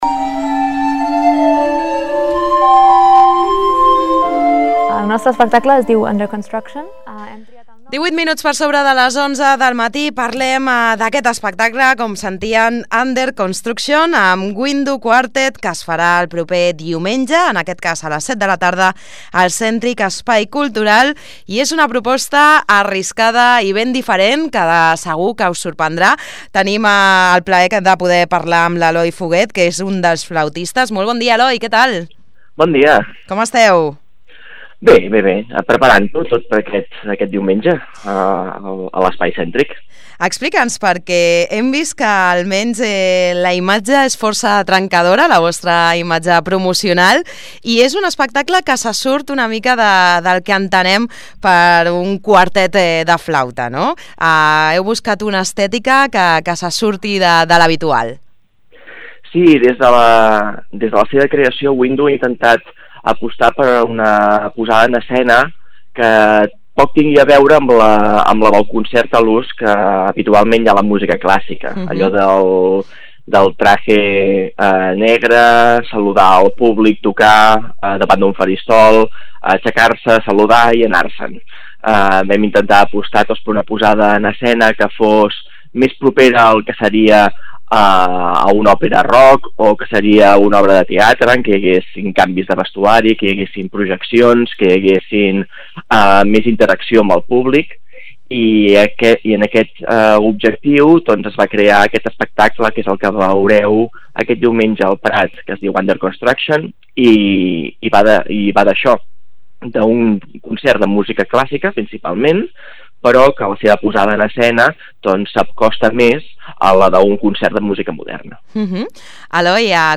17 gen. Entrevista a Planeta Prat